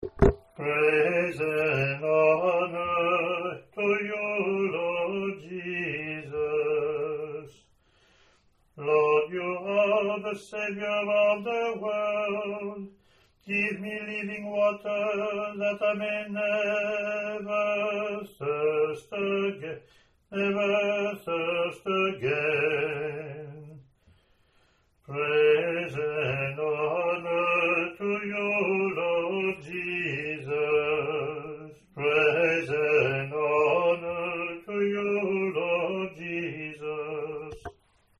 Gospel Acclamation
lt03-gospacc-eng-aae.mp3